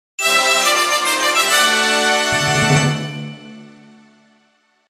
The sound that plays when completing a tour challenge